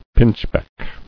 [pinch·beck]